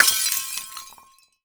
SHATTER_Glass_Medium_02_mono.wav